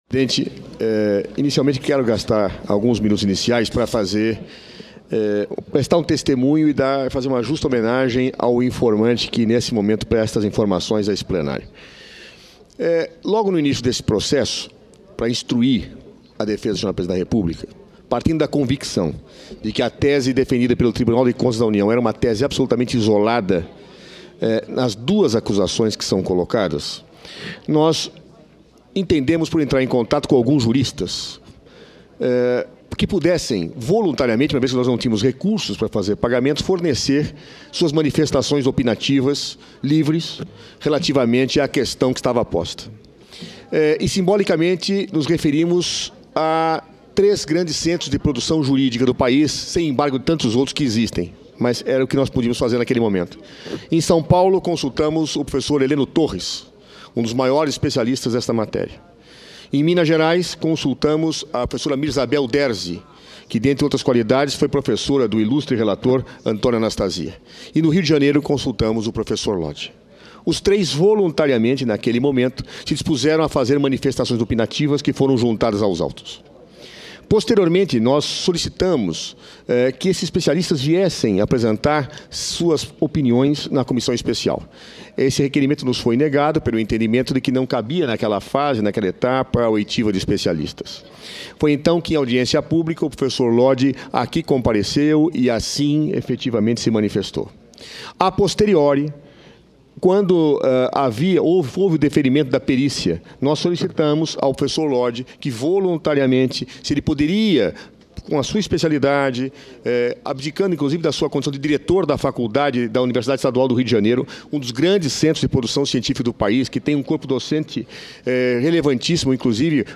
Inquirição de informante
Julgamento do Impeachment